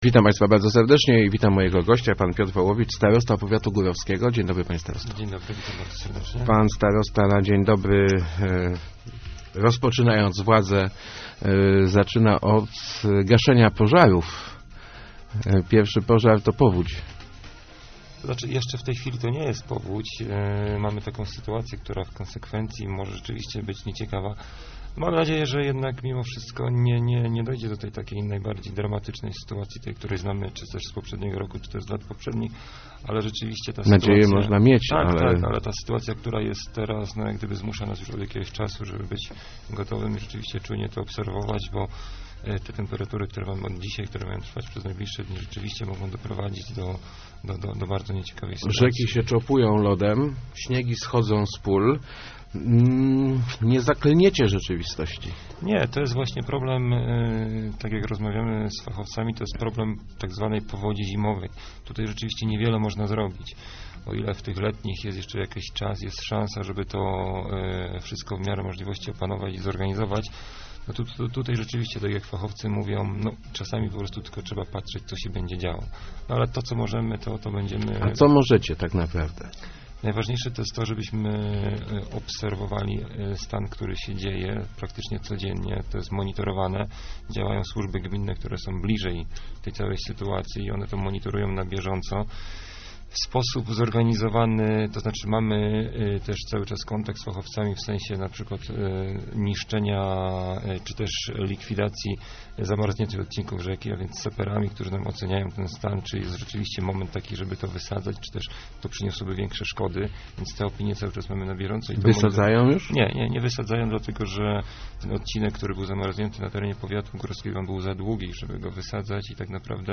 Nie stać nas na modernizację linii kolejowej Bojanowo-Góra - mówił w Rozmowach Elki starosta górowski Piotr Wołowicz - wątpię też, że stać na to gminę Góra. Najprawdopodobniej więc ostatnie połączenie kolejowe w powiecie zostanie zlikwidowane.